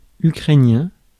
Ääntäminen
Synonyymit petit-russe petit-russien Ääntäminen France: IPA: /y.kʁɛ.njɛ̃/ Haettu sana löytyi näillä lähdekielillä: ranska Käännös Ääninäyte Adjektiivit 1.